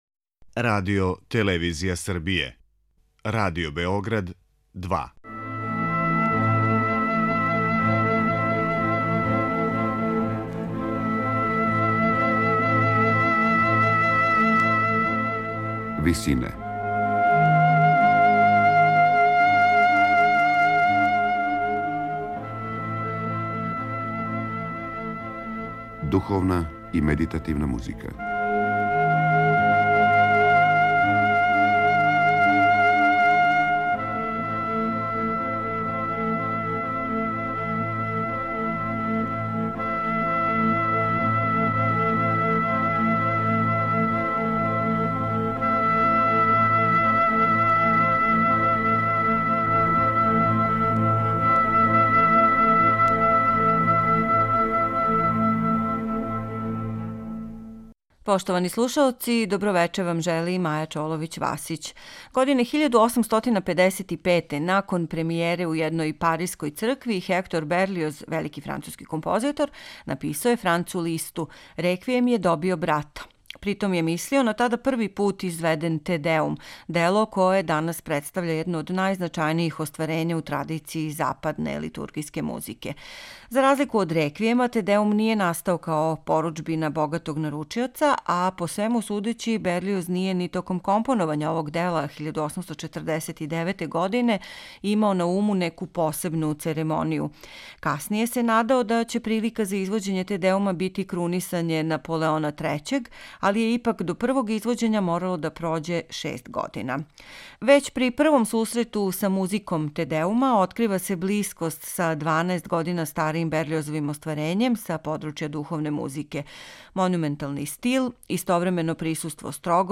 дело за соло тенор, три хора, симфонијски оркестар и оргуље